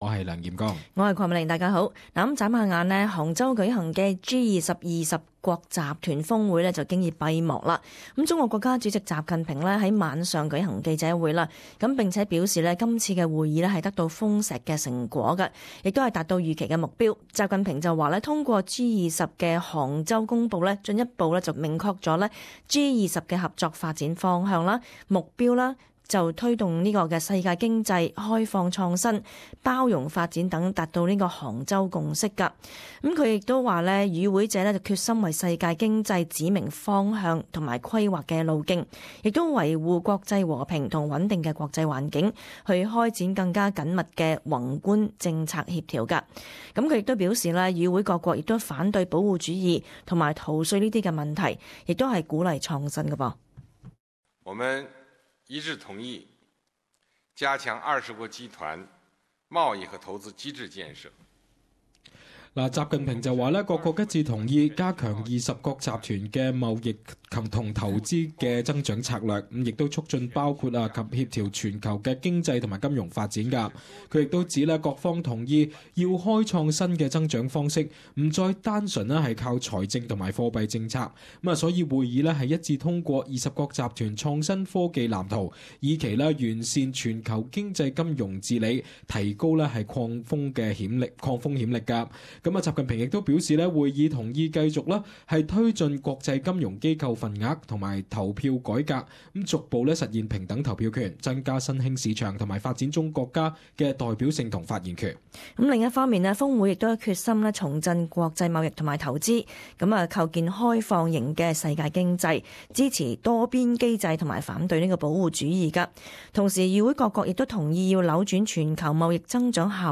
[時事報導] 二十國集團峰會閉幕